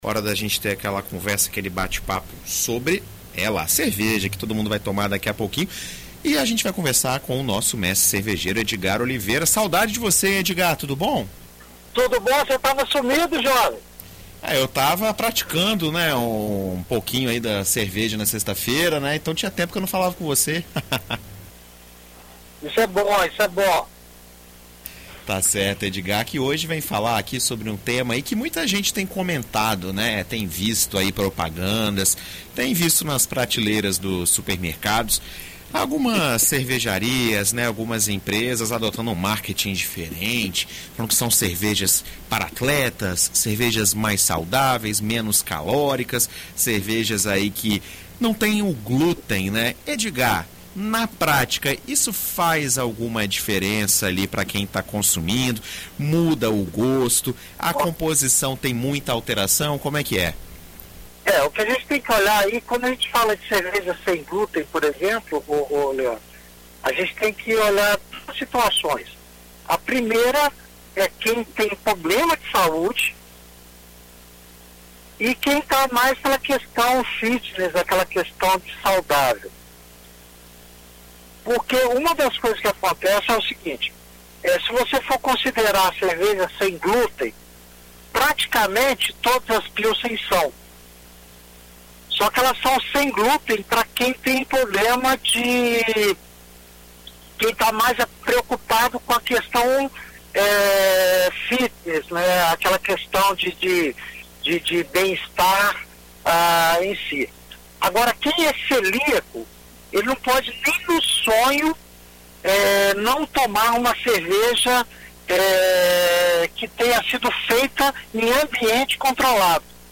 Em entrevista à BandNews FM ES nesta sexta-feira (03)